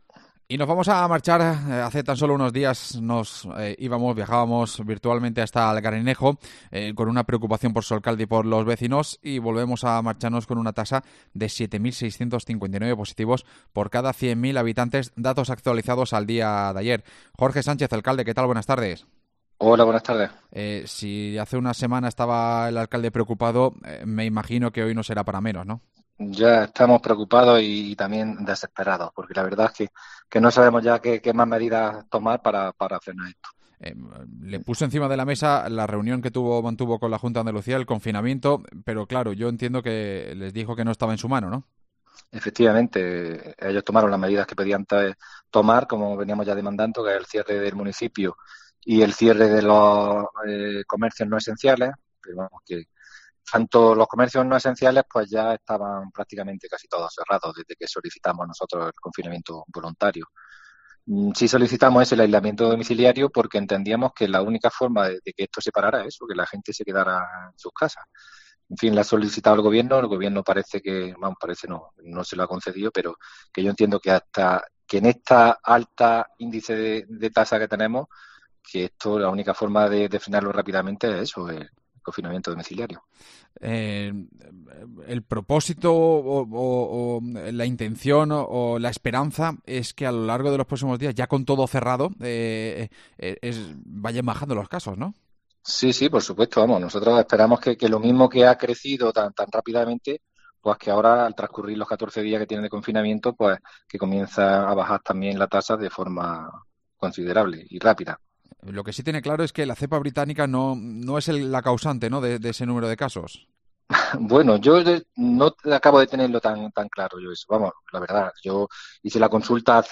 El alcalde de Algarinejo asegura en COPE que no se ha vacunado contra la COVID